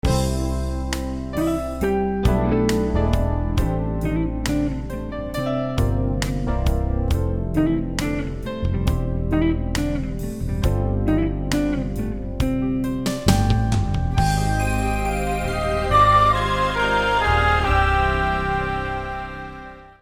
今回は表示される7項目から“Background”を選択した。
今回は“Peacful/Easy”を選択してみた。
サンプル曲